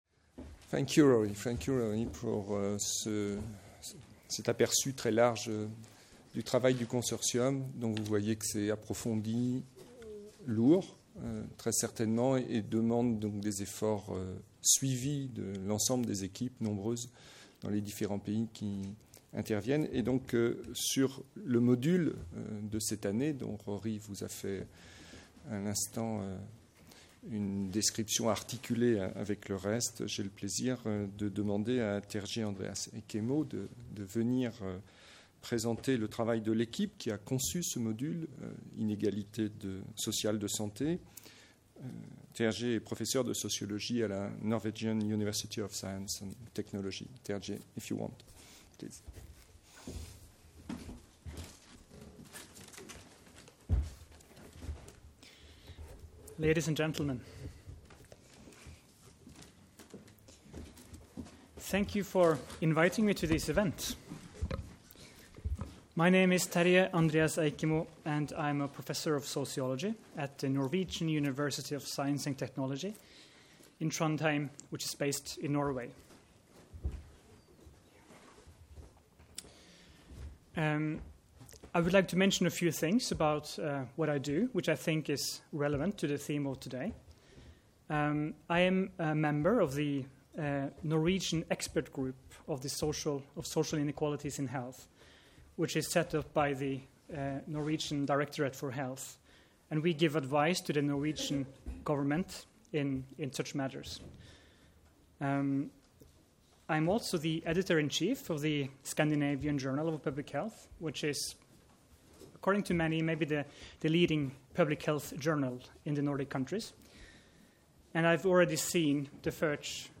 ESS Topline results presentation - Social Inequalities in Health